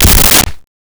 Creature Footstep 04
Creature Footstep 04.wav